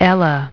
Транскрипция и произношение слова "ella" в британском и американском вариантах.